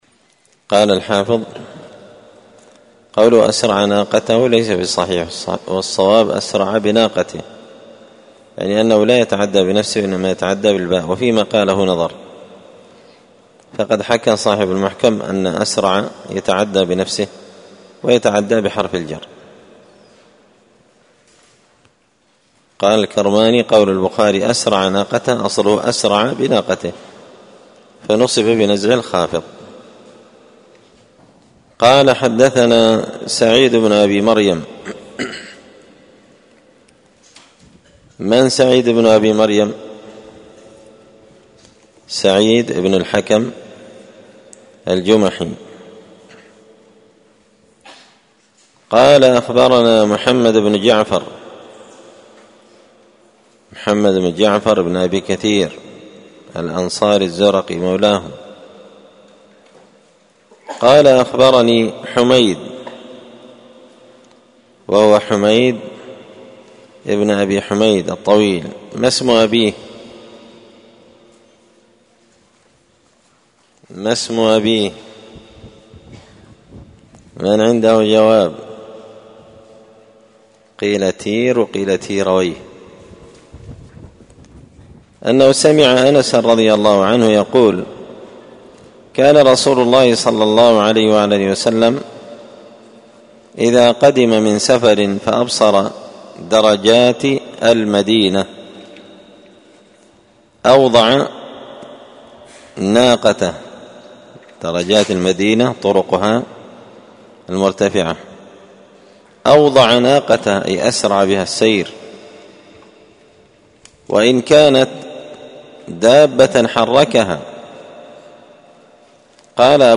الأثنين 20 محرم 1445 هــــ | الدروس، شرح صحيح البخاري، كتاب العمرة | شارك بتعليقك | 98 المشاهدات